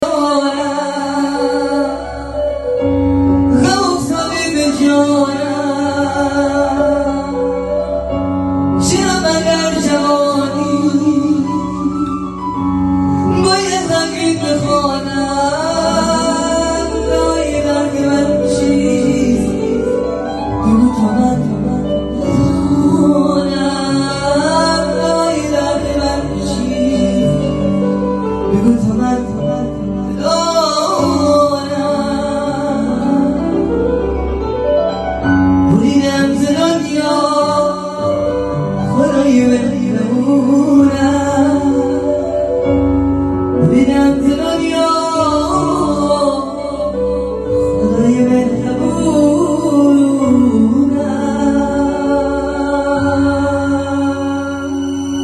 آهنگ محلی
در سبک غمگین